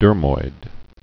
(dûrmoid)